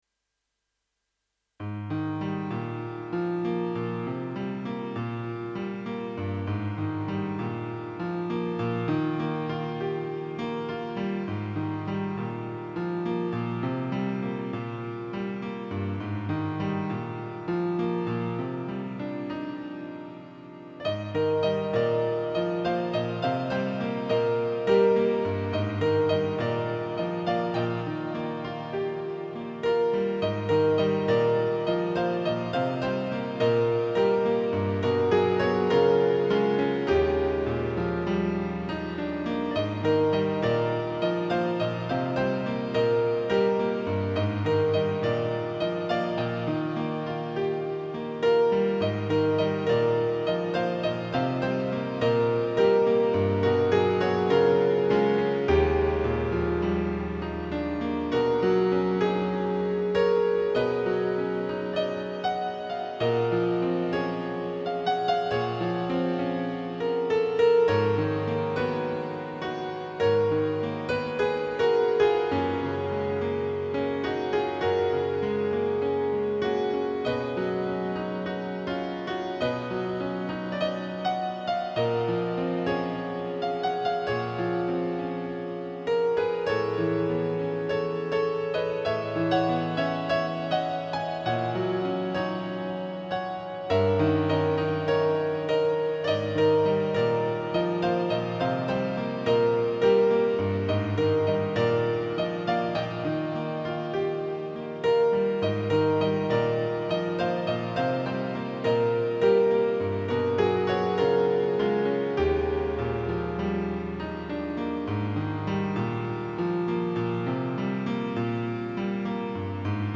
とても綺麗でかわいいピアノ曲ですね。